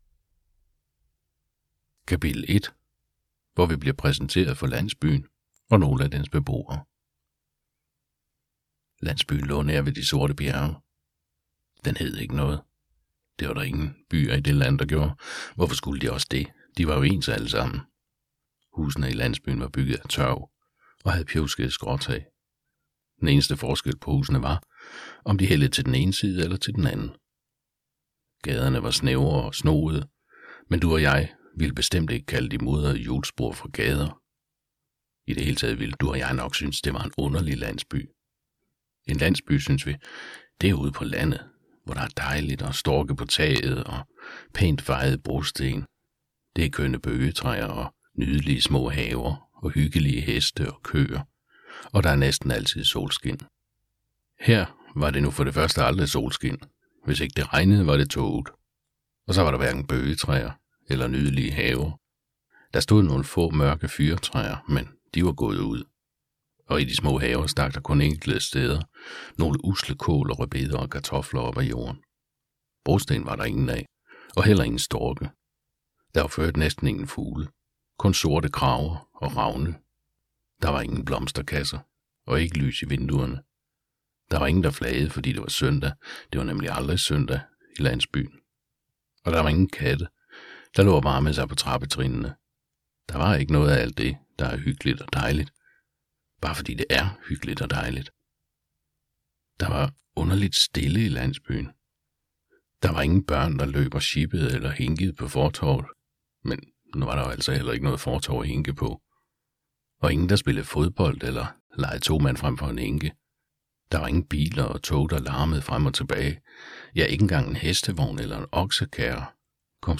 Hør et uddrag af Eventyret om Landet bag Bjergene Eventyret om Landet bag Bjergene Format MP3 Forfatter Anette Broberg Knudsen Bog Lydbog E-bog 249,95 kr.